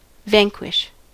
Ääntäminen
IPA : /ˈvæŋkwɪʃ/